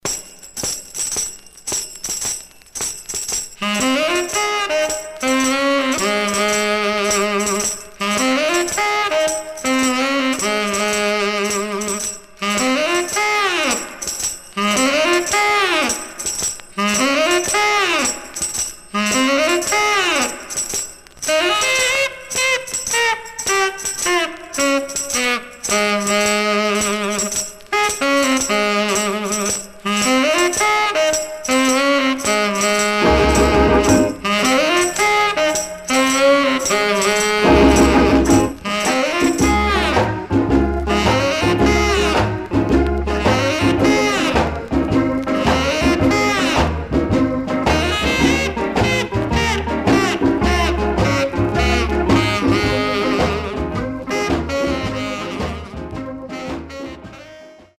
R&B Instrumental